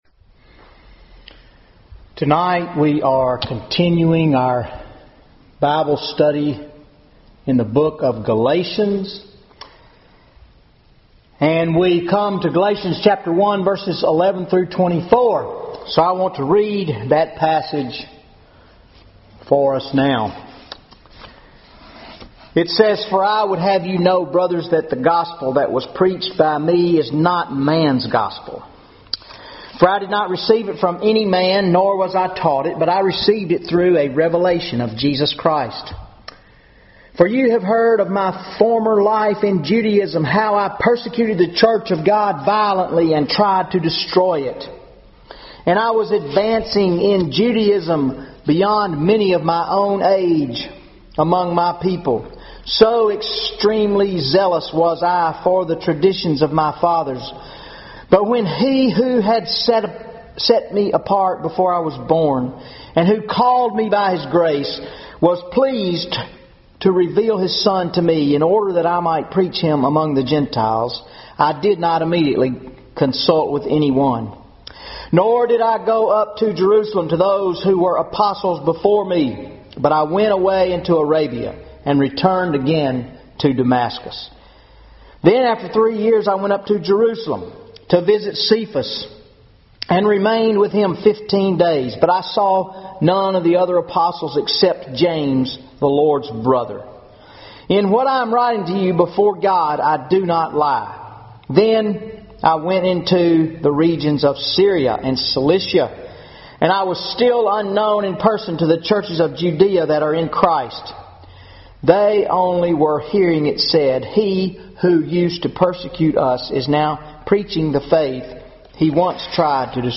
Wednesday Night Bible Study 11/12/2014 Galations 1:11-24 How Do We Know that Pauls Gospel is Authentic?